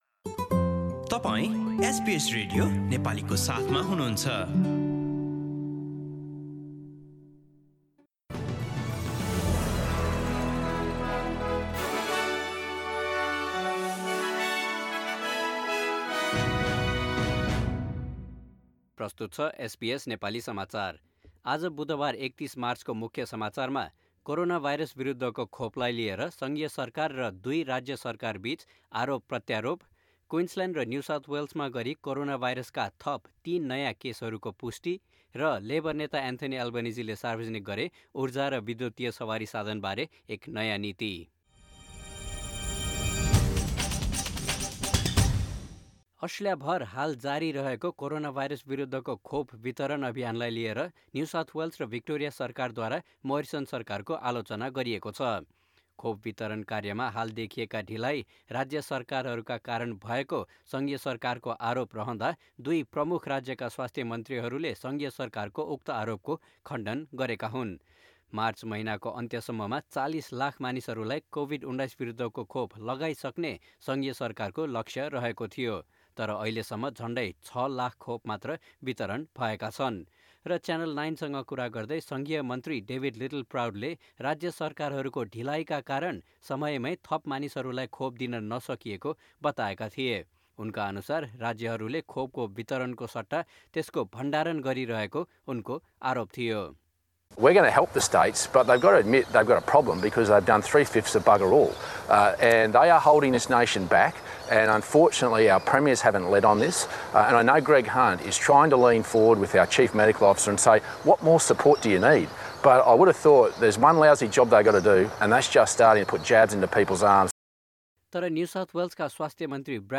Listen to the latest news headlines from Australia in Nepali.